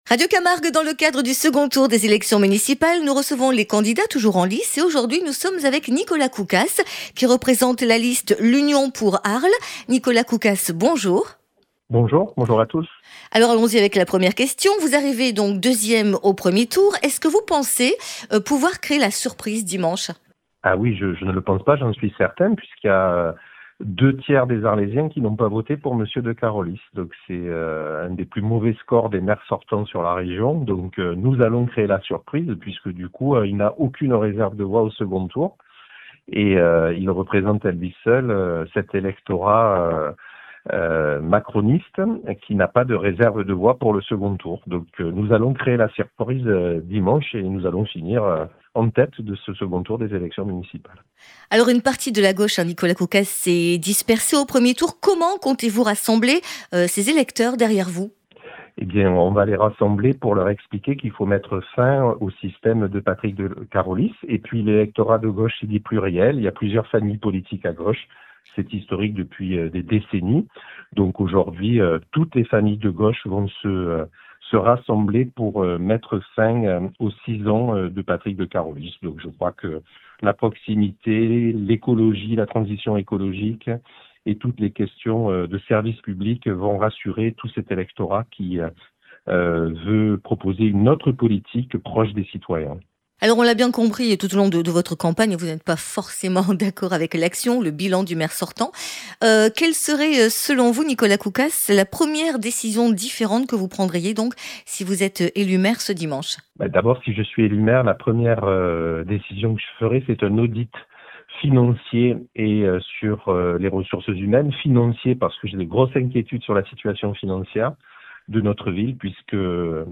Municipales 2026 : entretien avec Nicolas Koukas